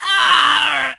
penny_hurt_vo_01.ogg